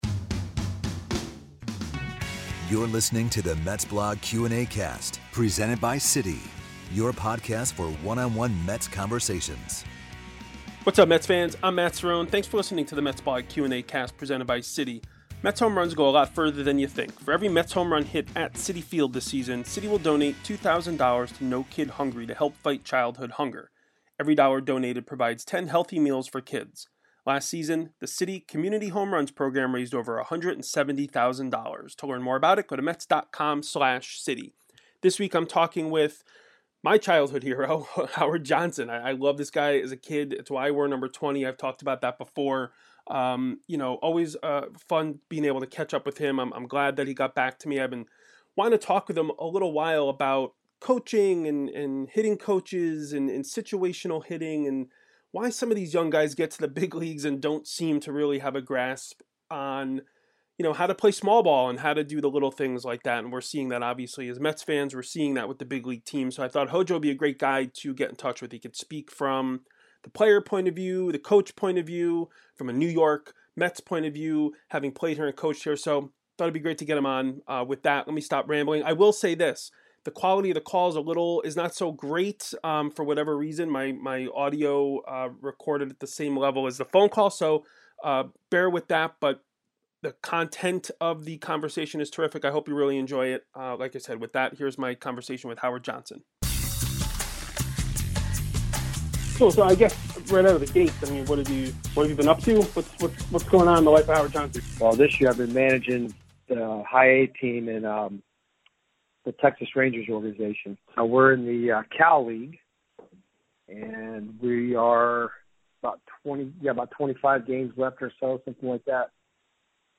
MetsBlog Q&Acast: Howard Johnson interview